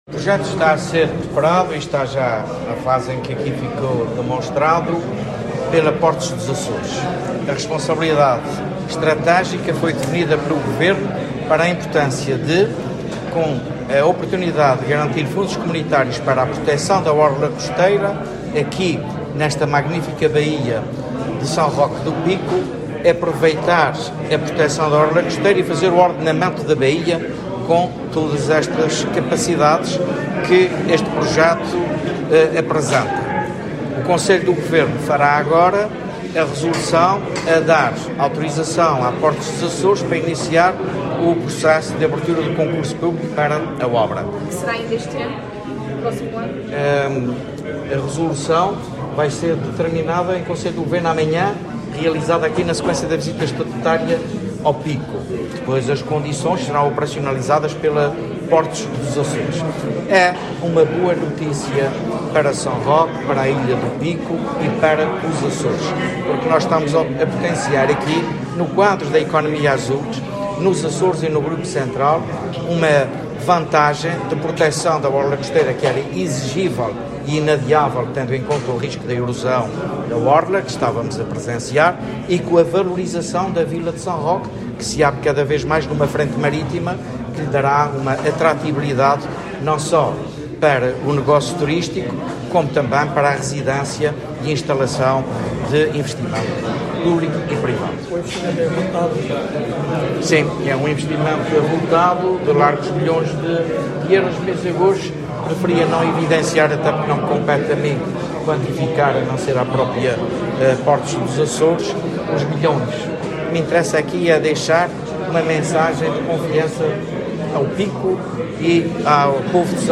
O Presidente do Governo Regional dos Açores, José Manuel Bolieiro, presidiu hoje à apresentação do projeto de proteção da orla costeira e ordenamento da baía do Cais do Pico, em São Roque, um ganho para o município, a ilha do Pico e toda a Região, assinalou.